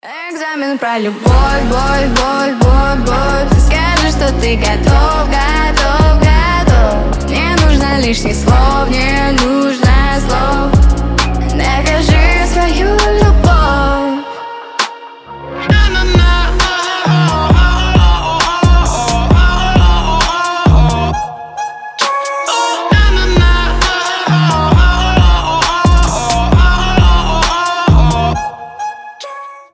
• Качество: 320, Stereo
поп
Trap
Bass